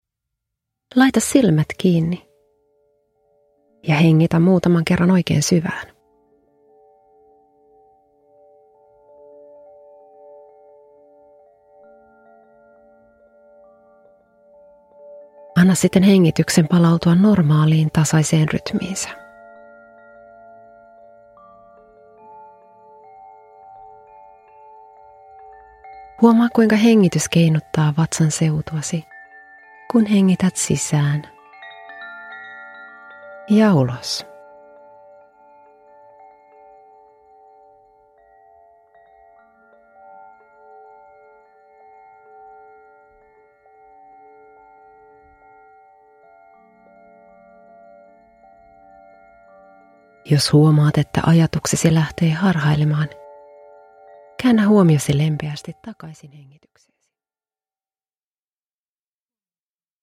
Stressinhallintameditaatio 10 min – Ljudbok – Laddas ner